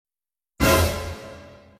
another shocked sound Meme Sound Effect
another shocked sound.mp3